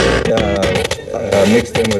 120BPMRAD4-R.wav